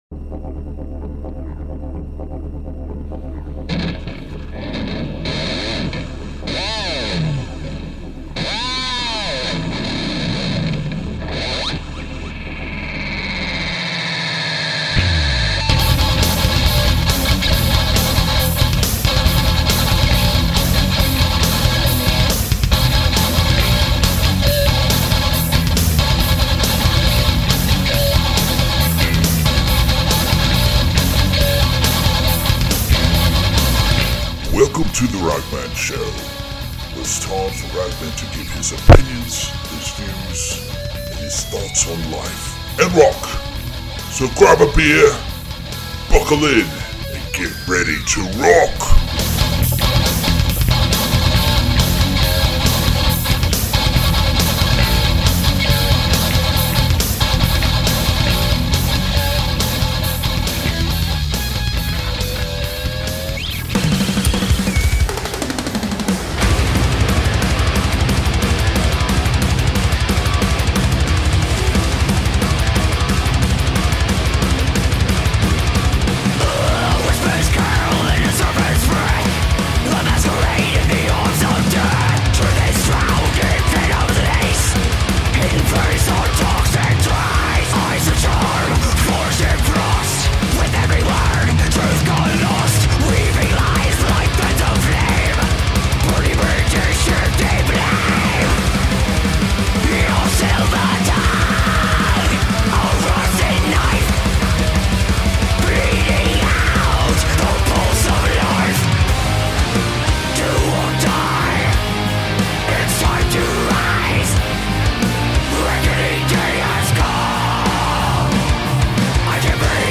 goes one on one with guitarist Joel Hoekstra (TSO, ex-Night Ranger, ex-Whitesnake)